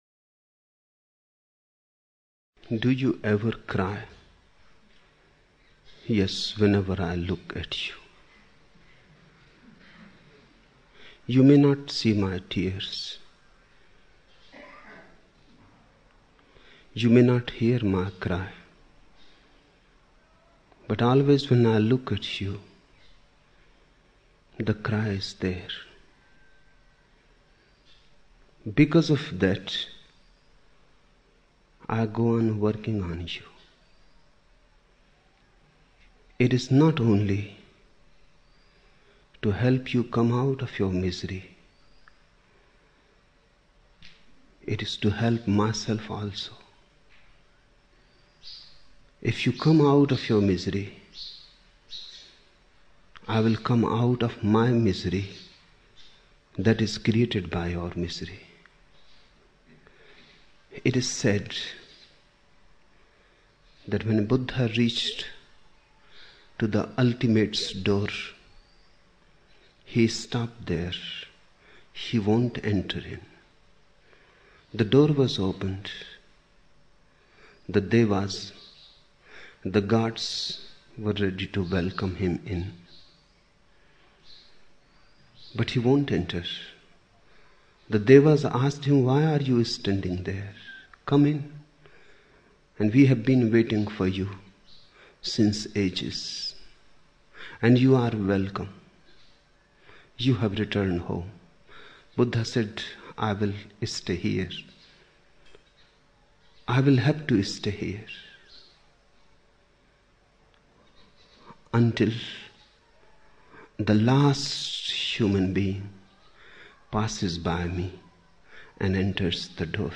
30 October 1975 morning in Buddha Hall, Poona, India